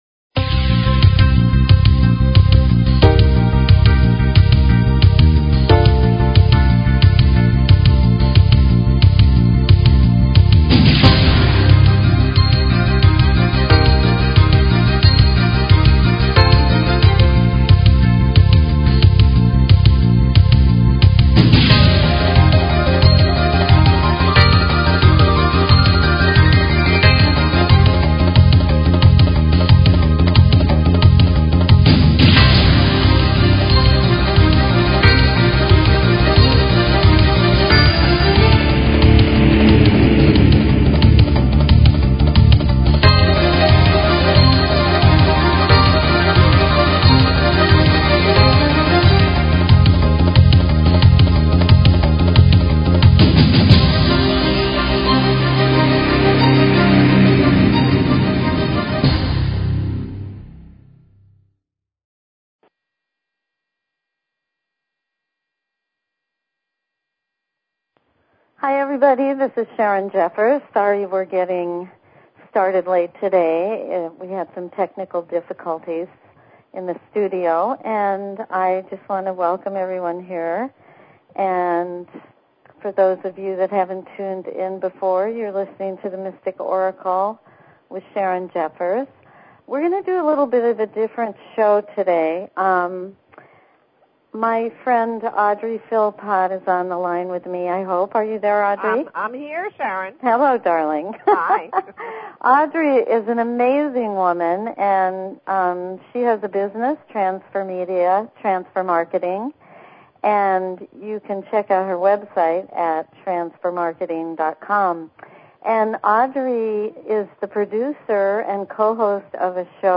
Open lines for calls.